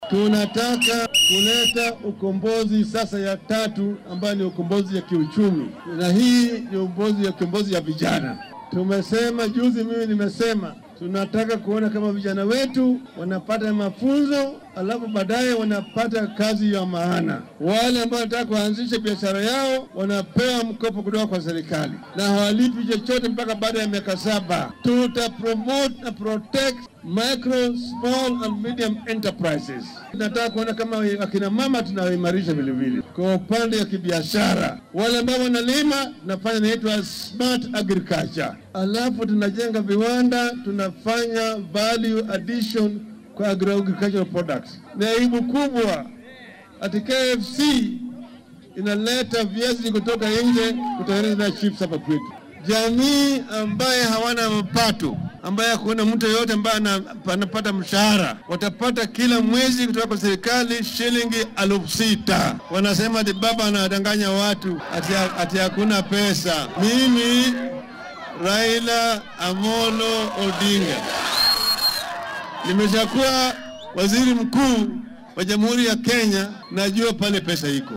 Madaxa xisbiga ODM Raila Odinga oo maanta isku soo bax ku qabtay deegaanka Kabete ee ismaamulka Kiambu ayaa faahfaahin ka bixiyay qorshaha uu maamulkiisa dhaqangelin doono haddii uu bisha siddeedaad ku guulaysto xilka madaxtinimo.